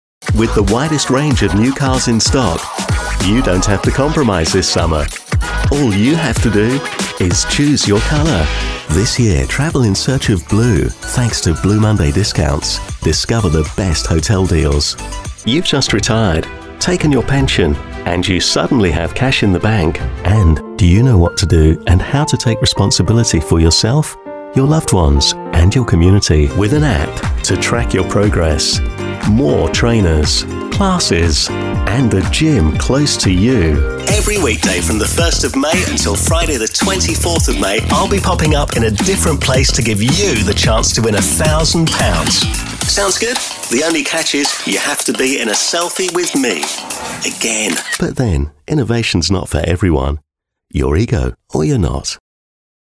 Englisch (Britisch)
Zuverlässig, Freundlich, Warm, Corporate, Natürlich
Kommerziell
Er arbeitet aus seinem professionell ausgestatteten Studio.